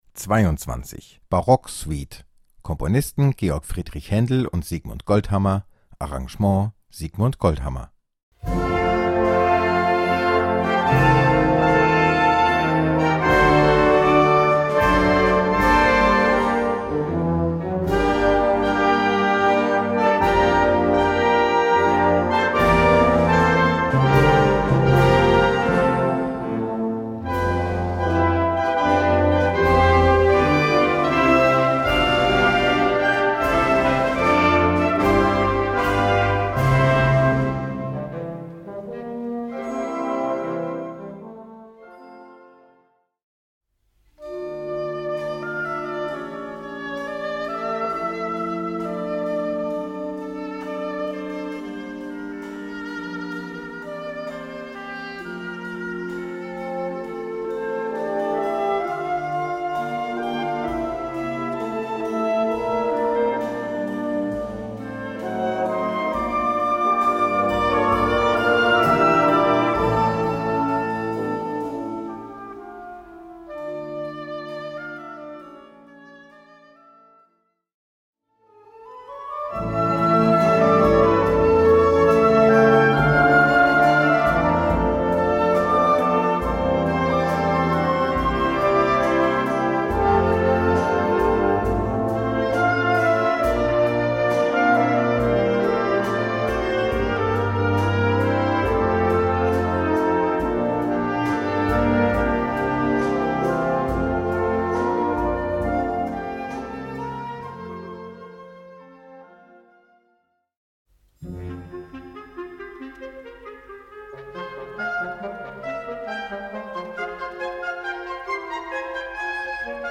Gattung: Suite
Besetzung: Blasorchester
in 5 Sätzen (Präludio; Air; Courante; Adagio; Allegro)